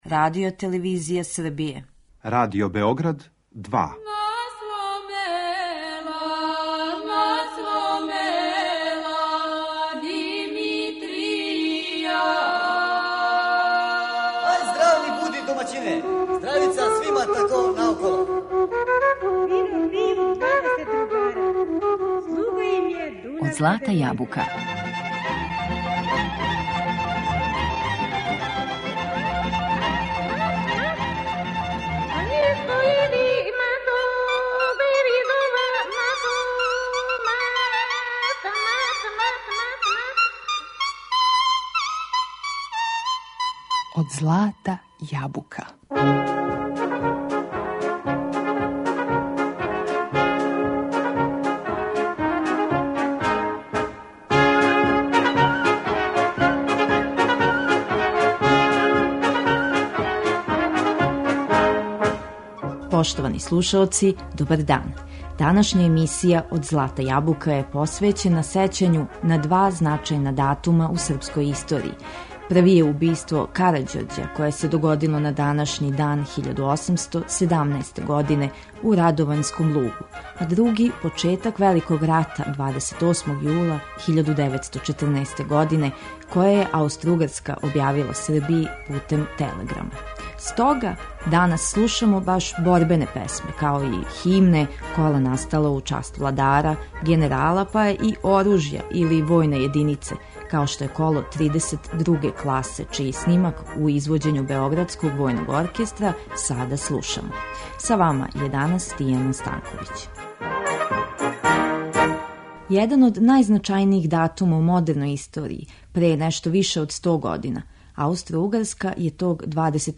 Данашње издање емисије Од злата јабука посвећено је сећању на два велика догађаја у српској историји, убиству Карађорђа које се десило 26. јула 1817. године у Радовањском лугу и почетак Великог рата 28. јула 1914. године које је Аустроугарска објавила Србији путем телеграма. У емисији ћемо слушати борбене песме снимљене у другој деценији 20. века као и кола у извођењу Београдског војног оркестра.